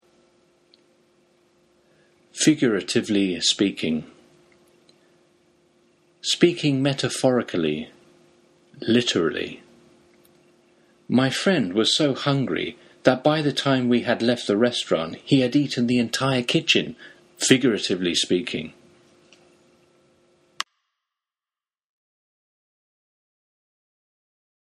つまり、figuratively speaking とは、文字通りの真実ではなく、比喩や強調として話す、という意味です。 英語ネイティブによる発音は下記のリンクをクリックしてください。